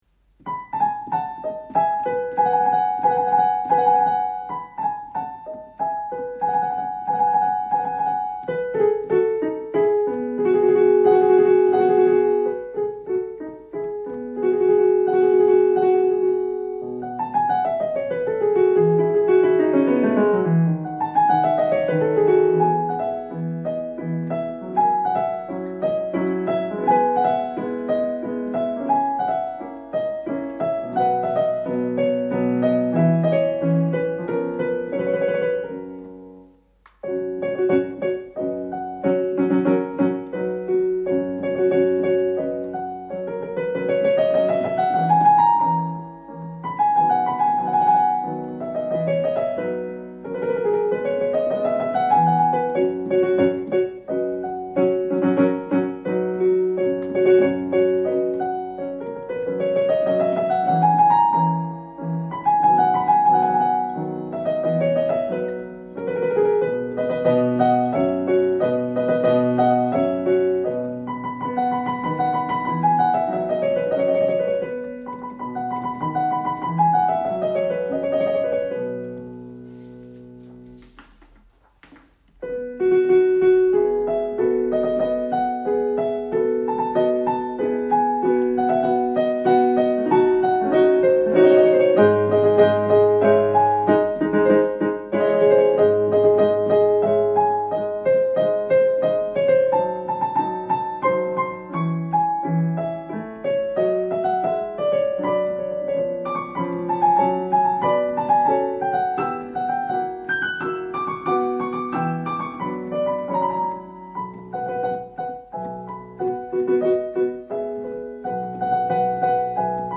Culte du 29 novembre 2020